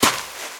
High Quality Footsteps
STEPS Sand, Walk 03.wav